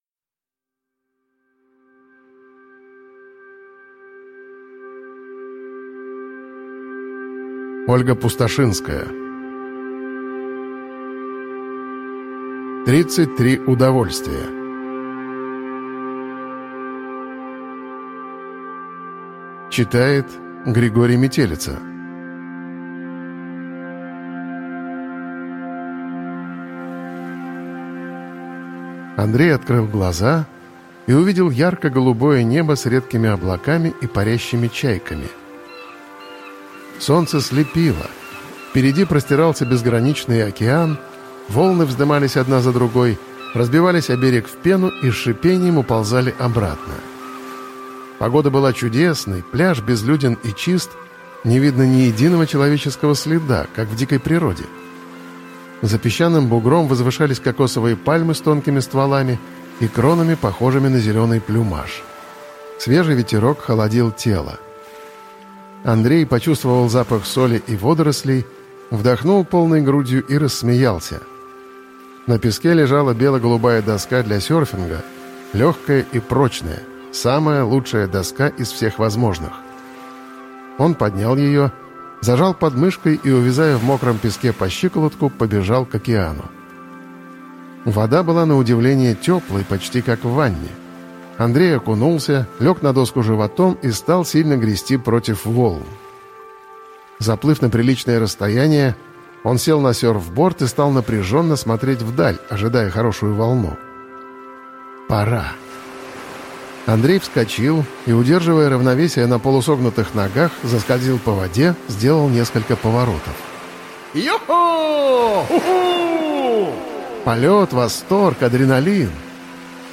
Аудиокнига 33 удовольствия | Библиотека аудиокниг